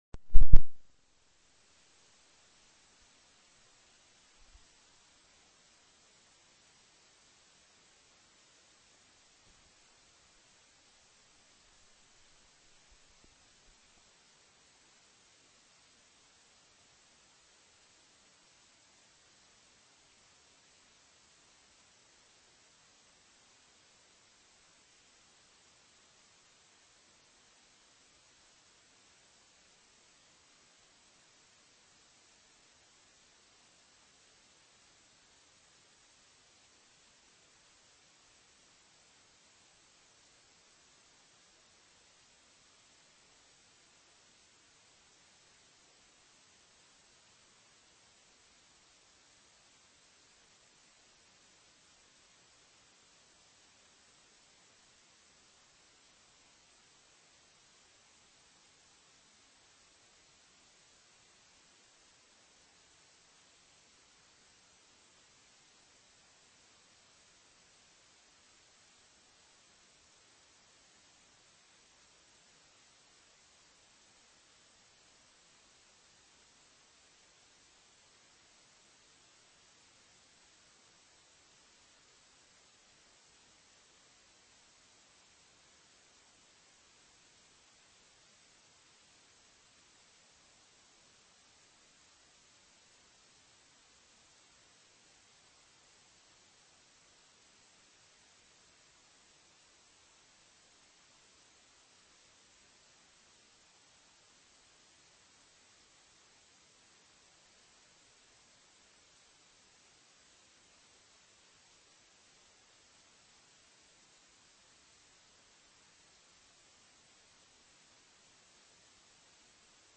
Chief Beck Takes Media Inquiries after Police Commission Meeting 8-4-15